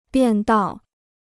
便道 (biàn dào): pavement; sidewalk.